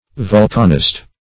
Vulcanist \Vul"can*ist\, n.